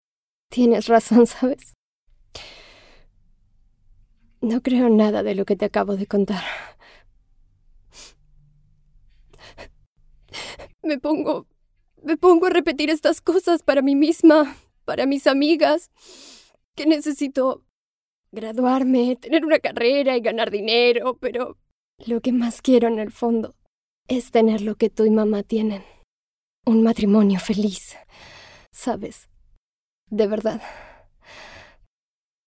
Feminino
Espanhol - América Latina Neutro
DRAMA
Voz Jovem 00:32